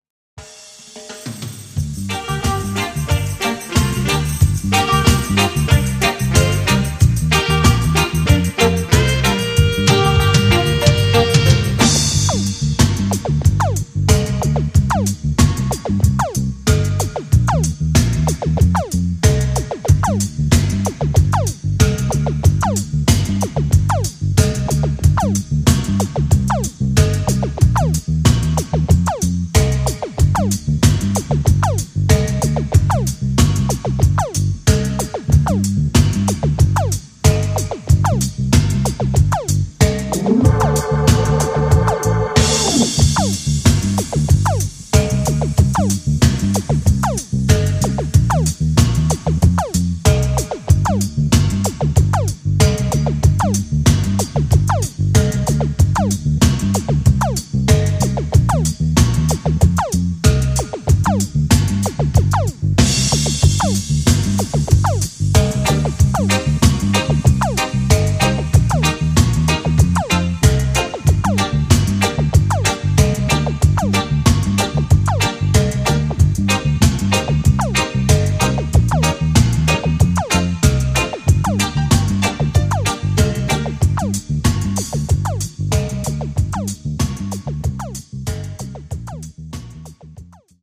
An obscure cover version, with a star-studded rhythm section
B1 – Instrumental Version